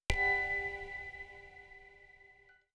SFX_Menu_Confirmation_02.wav